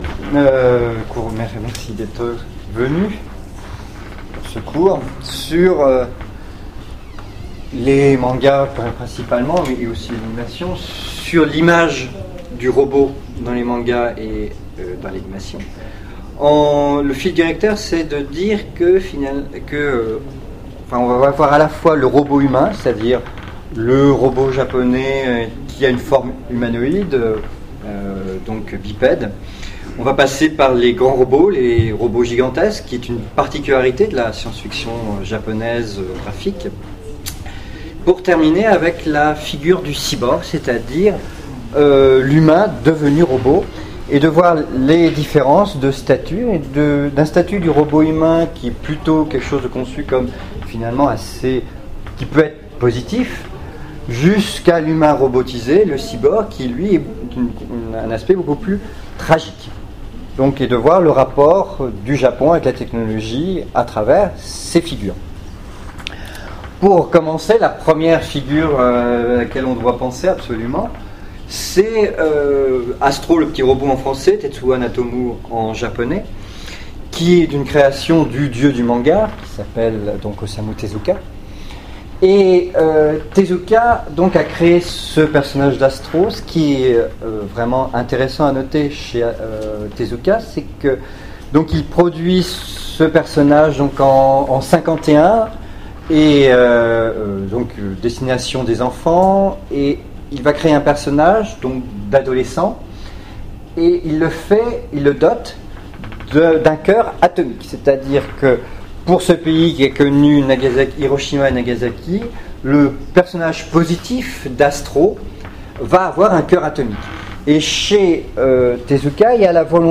Utopiales 2014 : Cours du soir - Du robot-humain à l'humain robotisé : l'homme et la machine dans le manga
Conférence